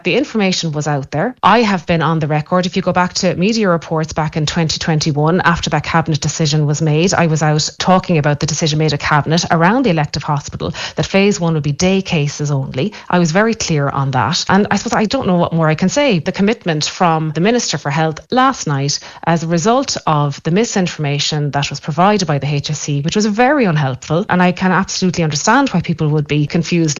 Education Minister and Fine Gael TD for Galway West Hildegarde Naughton says the situation is “unhelpful.”…………….